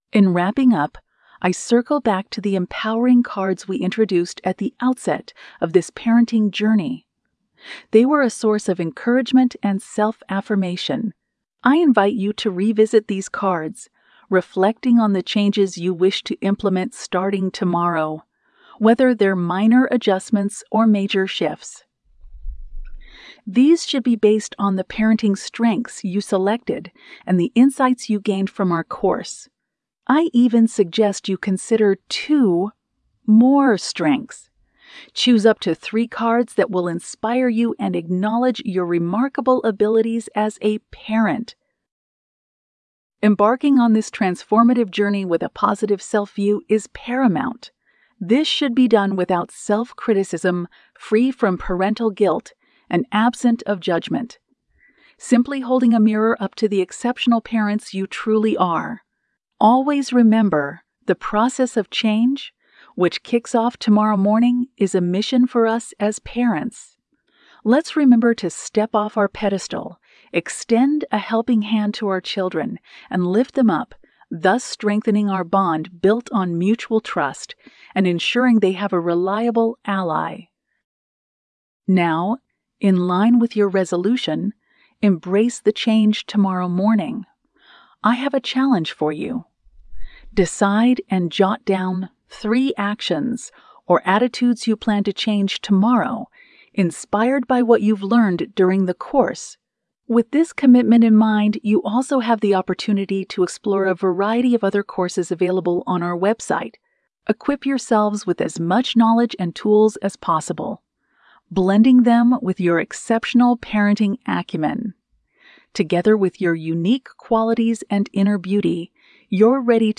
Lecture 8: Summary Healthy Relationship